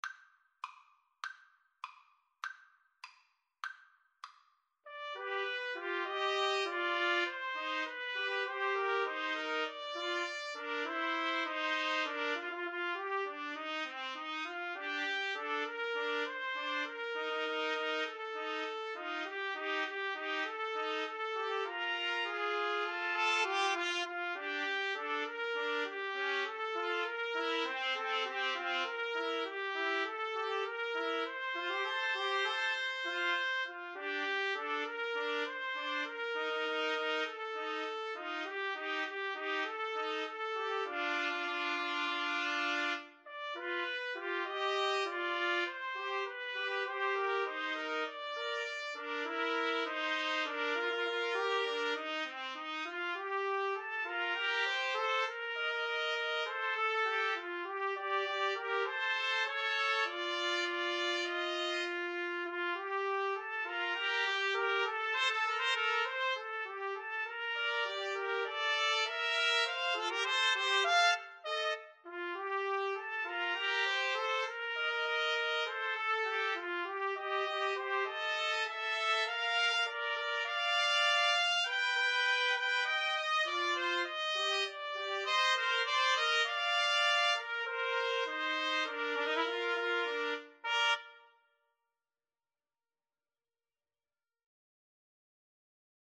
Trumpet 1Trumpet 2Trumpet 3
2/4 (View more 2/4 Music)
Tempo di Marcia
Pop (View more Pop Trumpet Trio Music)